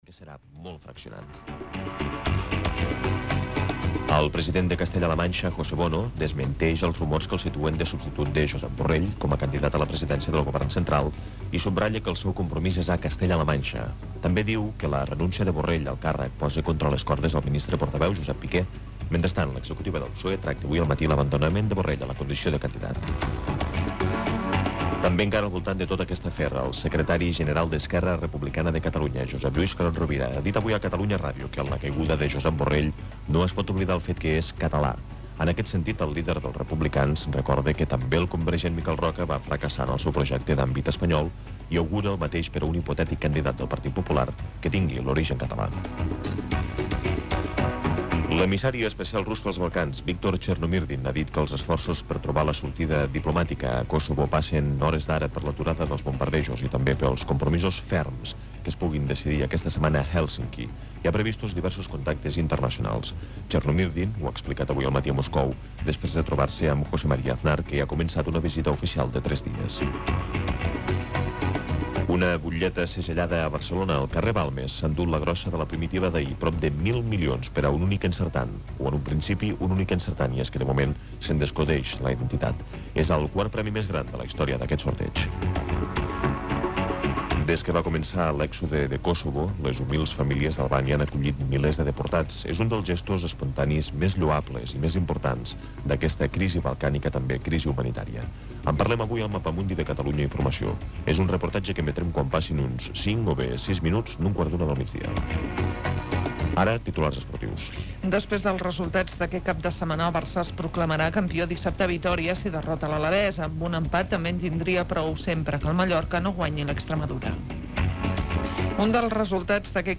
Indicatius de Catalunya Cultura.
Informatiu